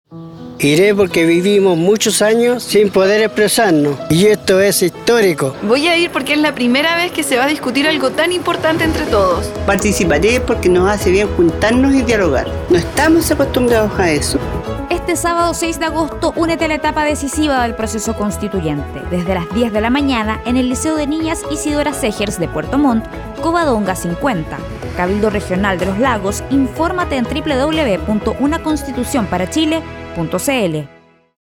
Audio promoción testimonial cabildos regionales, Región de Los Lagos
Audio promoción testimonial con detallada información de hora y lugar de realización de los cabildos regionales, Región de Los Lagos.